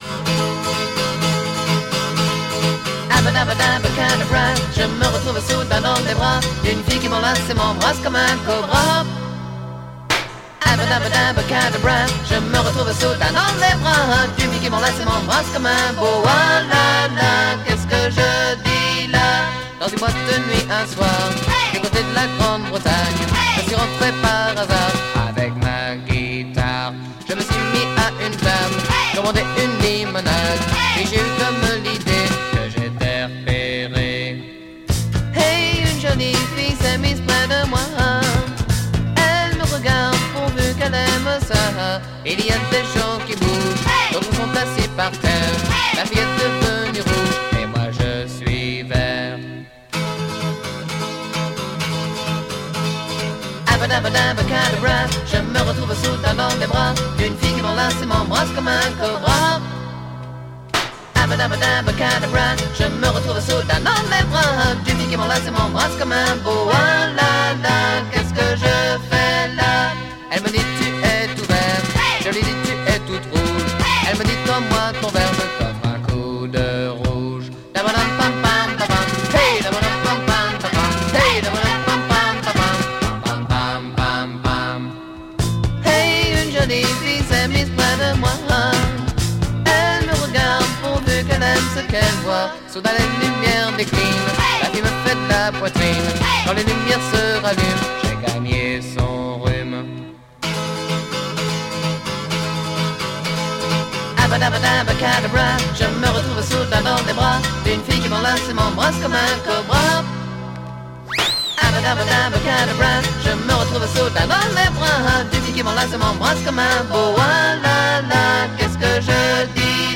French Pop-sike single